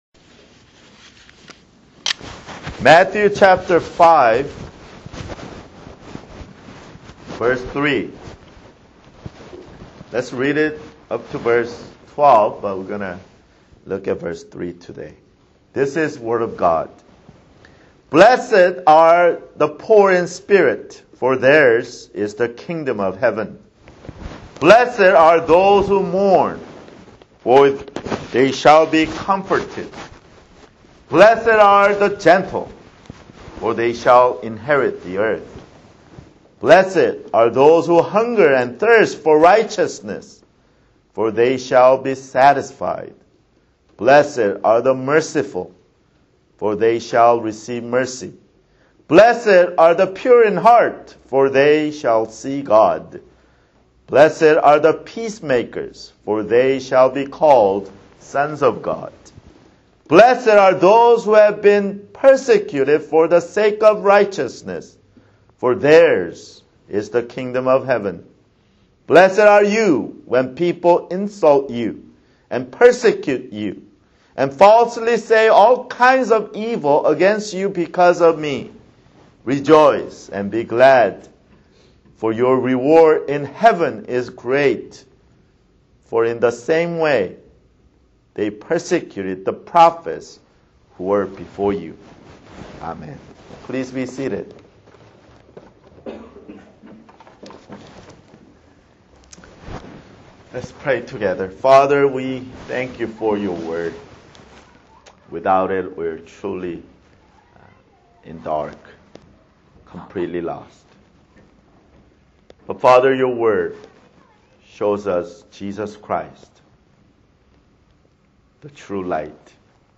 Download MP3 (Right click on the link and select "Save Link As") Labels: Sermon - Matthew